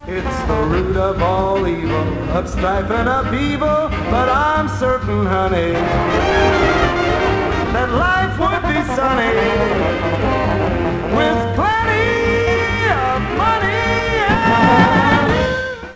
Playing on the house radio